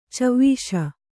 ♪ cavvīśa